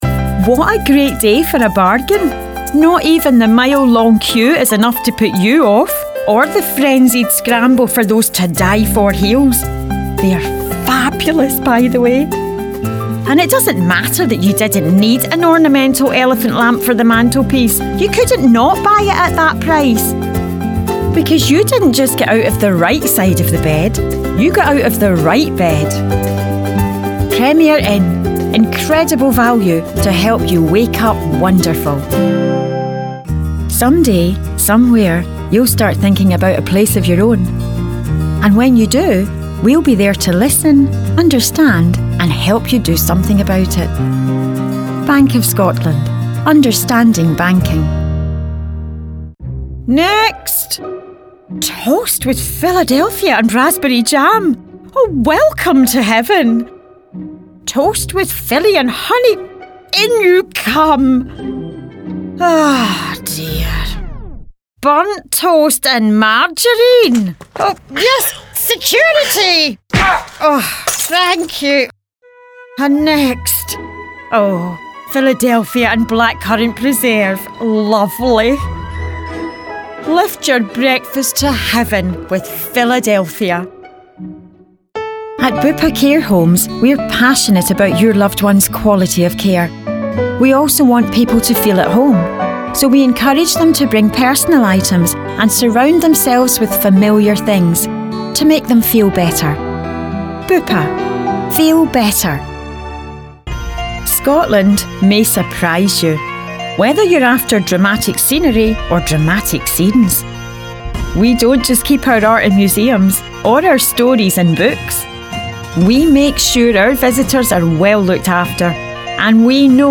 Adult
Has Own Studio
scottish | natural
COMMERCIAL 💸